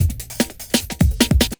41 LOOP08 -R.wav